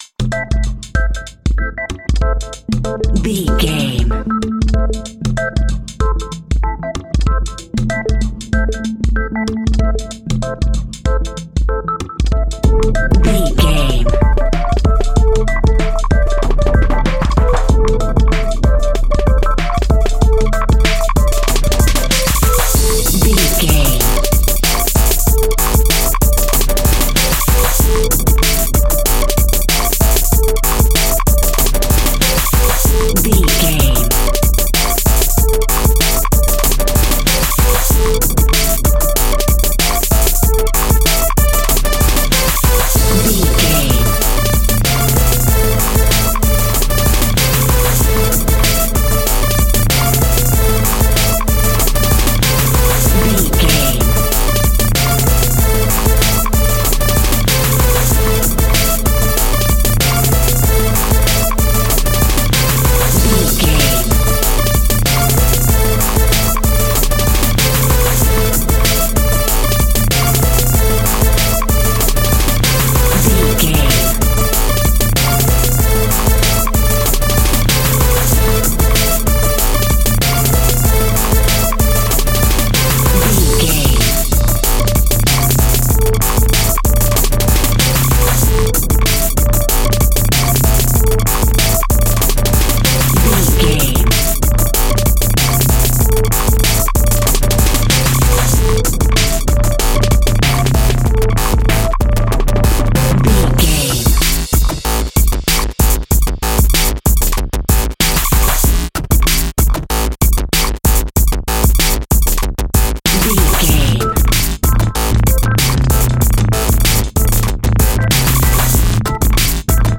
Aeolian/Minor
Fast
hip hop
hip hop instrumentals
hip hop synths
synth lead
synth bass
synth drums
turntables